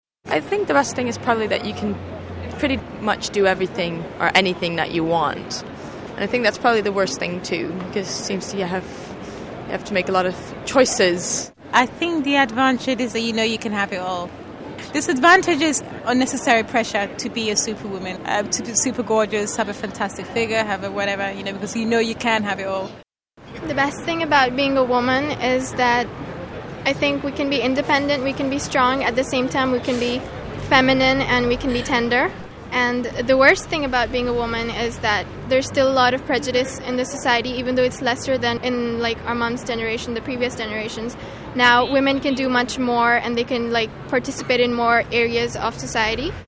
Every week we ask you a different question. Hear what people in London say, then join the conversation!